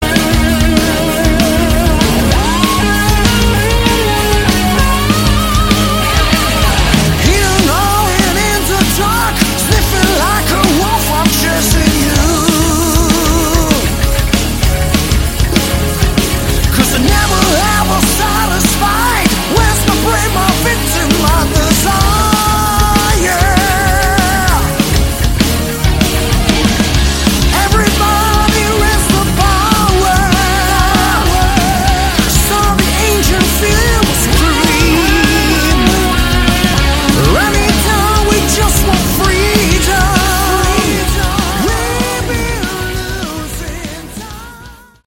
Category: Hard Rock
drums
guitar
keyboards
bass
vocal